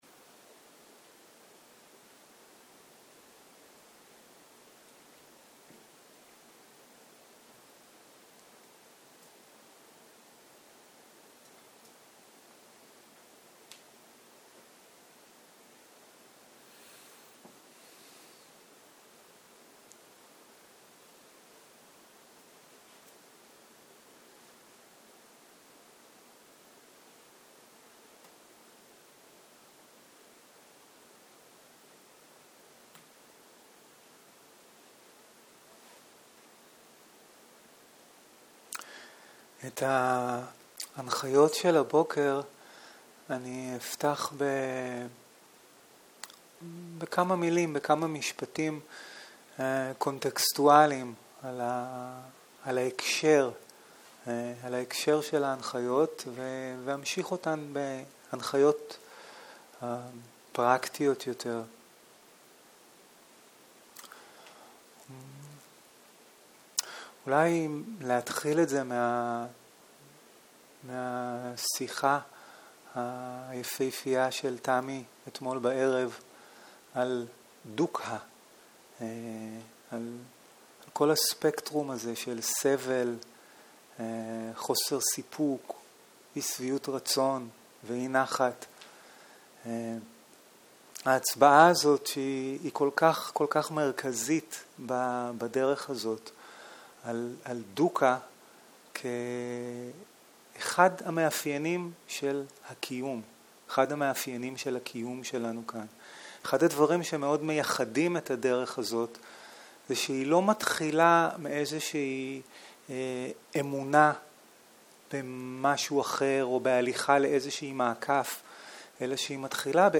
Dharma type: Guided meditation שפת ההקלטה